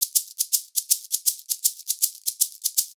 80 SHAK 17.wav